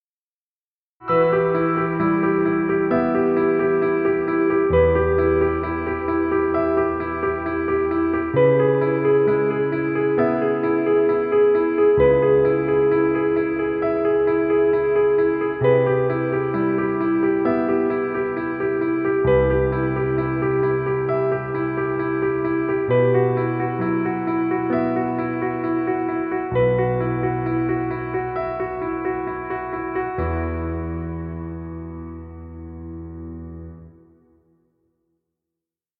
Piano music. Background music Royalty Free.